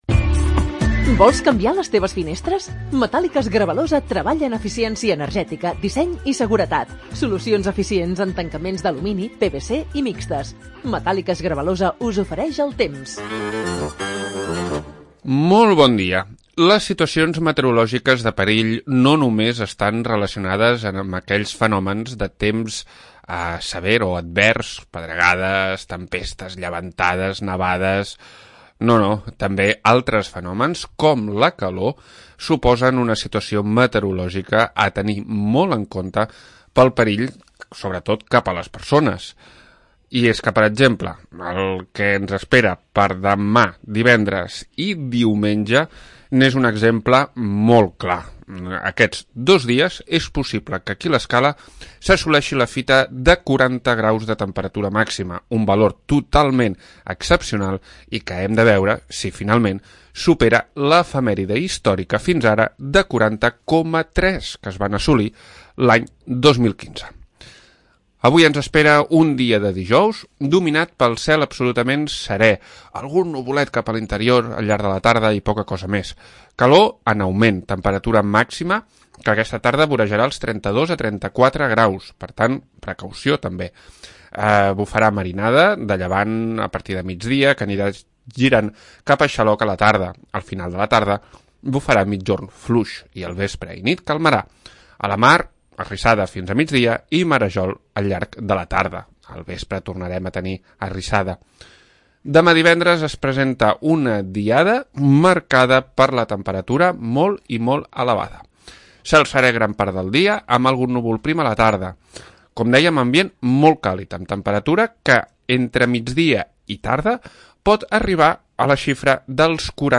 Previsió meteorològica 21 de Juliol de 2022